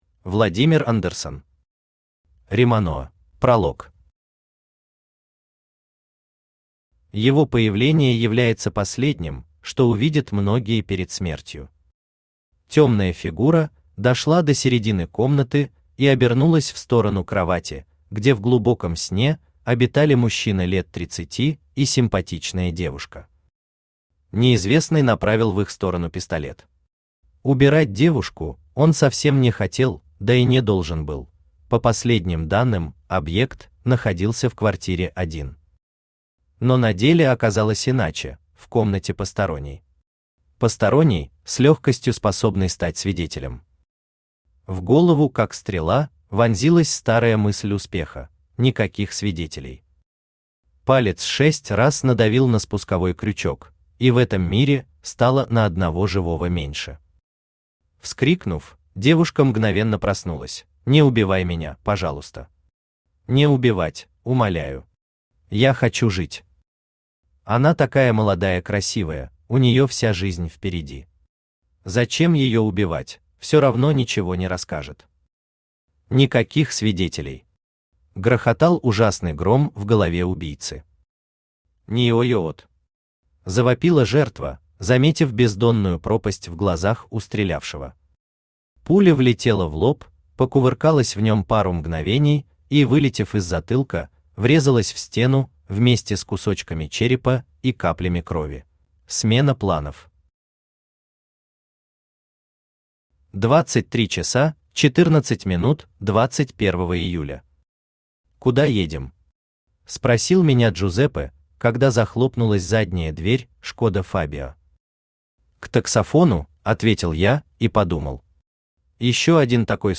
Аудиокнига Риманоа | Библиотека аудиокниг
Aудиокнига Риманоа Автор Владимир Андерсон Читает аудиокнигу Авточтец ЛитРес.